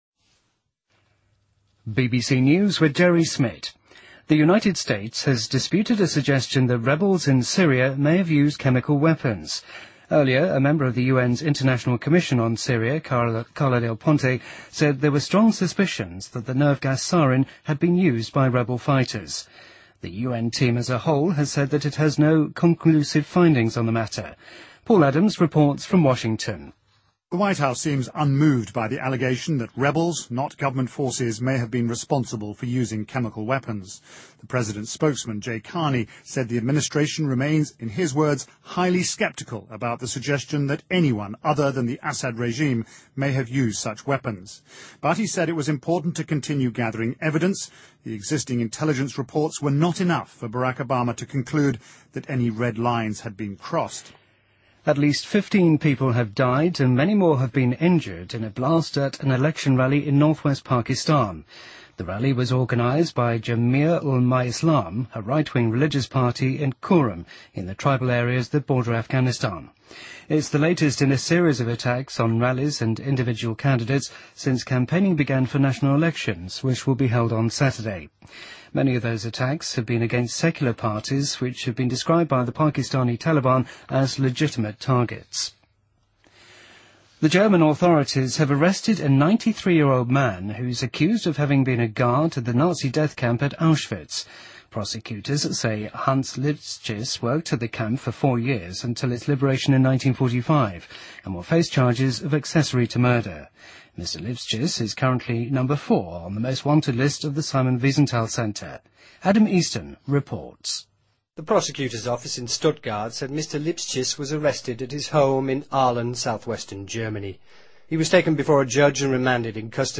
BBC news,2013-05-07